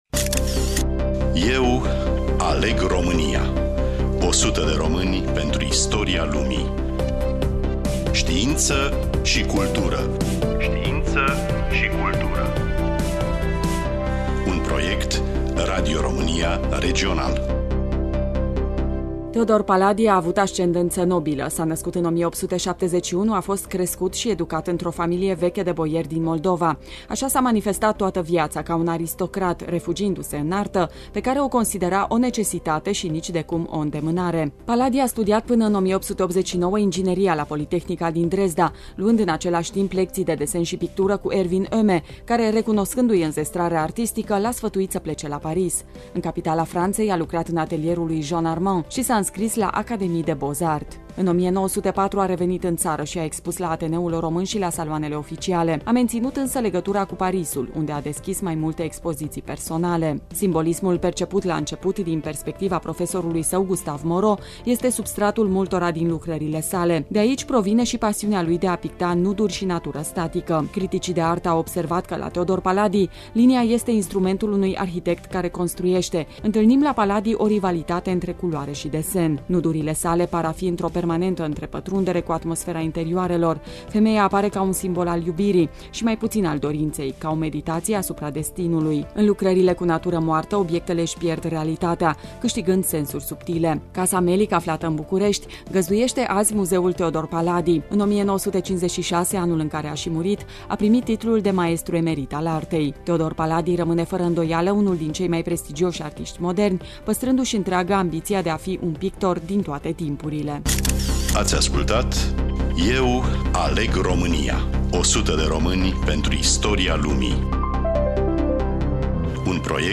Studioul: Radio România Tg.Mureş